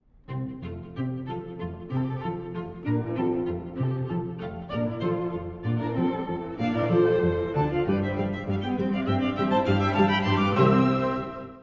まるで色づいた森を思わせるよう。
とても軽快で充実した楽章です。
随所に現れる3拍目のアクセントがスパイスとなっています。
響きを増しながら躍動するさまは、まさに弦楽交響曲ともいえます。